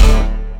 emn kick synth.wav